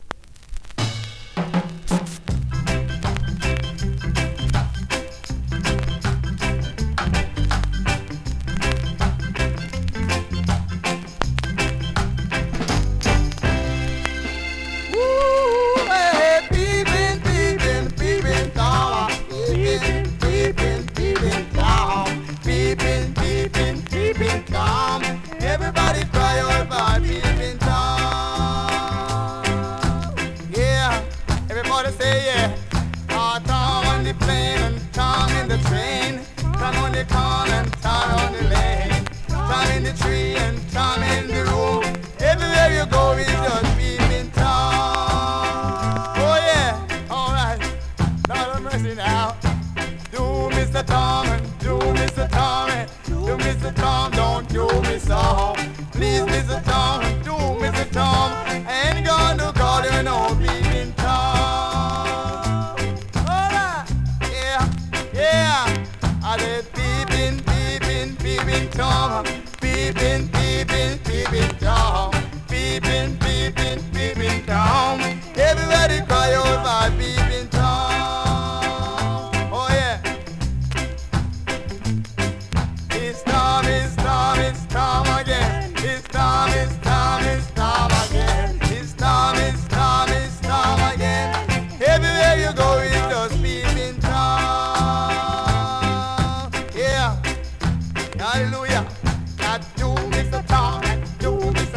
NEW IN!SKA〜REGGAE
スリキズ、ノイズそこそこありますが